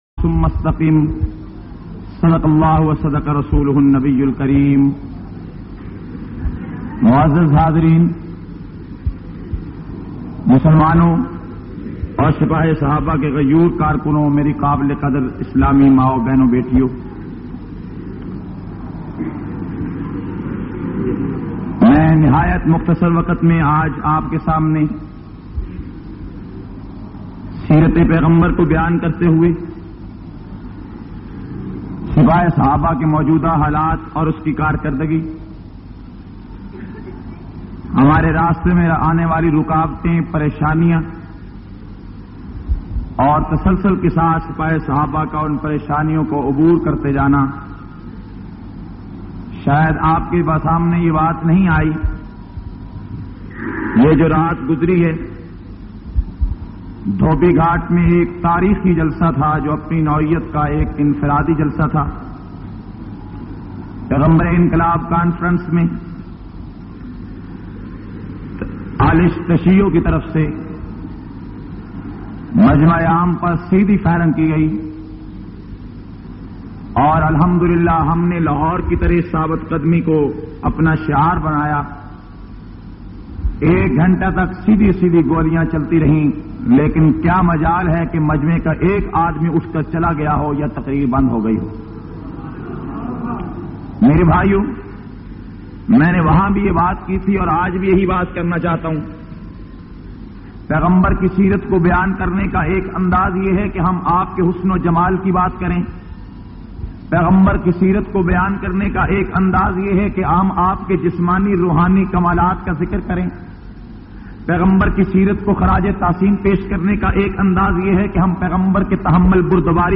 94- Salana conference Railway ground Attok.mp3